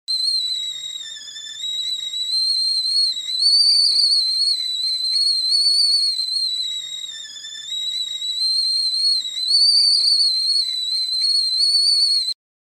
Звуки чайника
Звук свистящего чайника